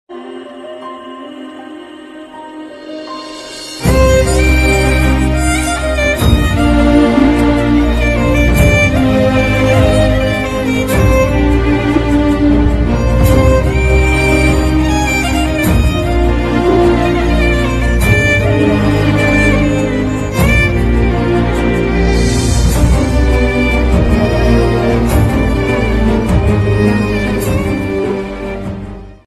Category: Malayalam Ringtones